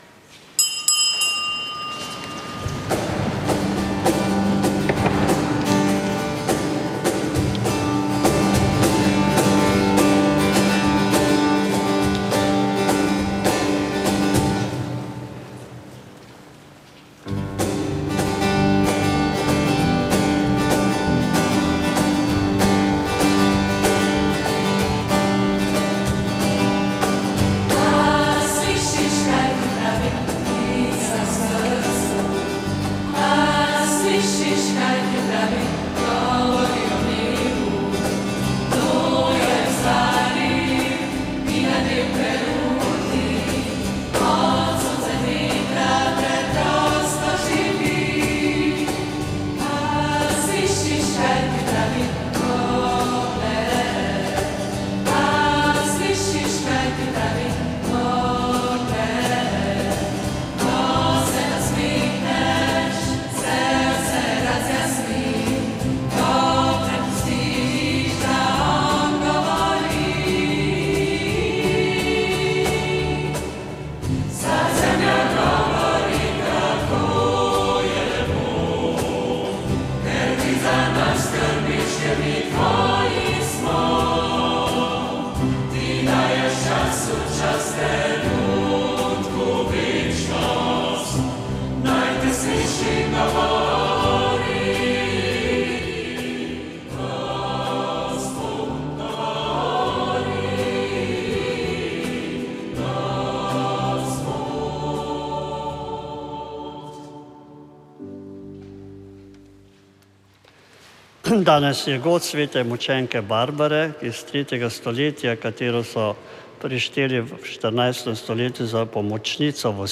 Sveta maša
Sv. maša iz župnijske cerkve sv. Jožefa in sv. Barbare iz Idrije 30. 11.
Pel pa župnijski pevski zbor.